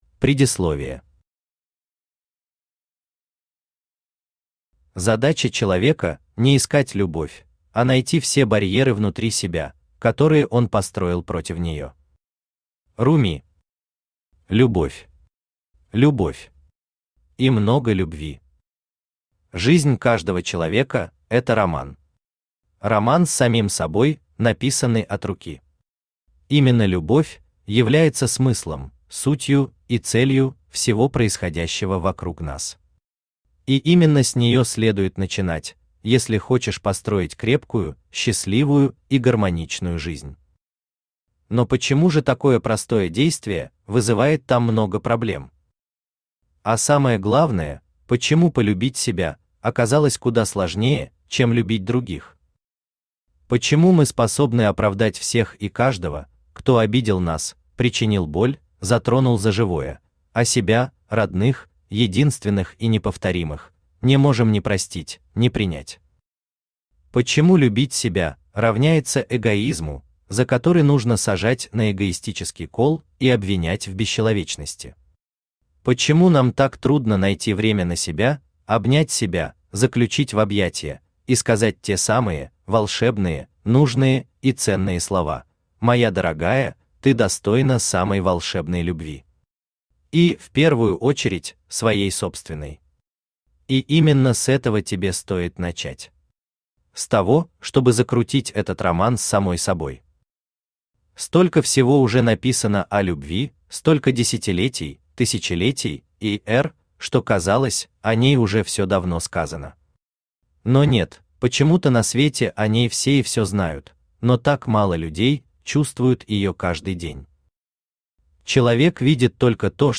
Аудиокнига Это и есть твоя первая любовь | Библиотека аудиокниг
Читает аудиокнигу Авточтец ЛитРес.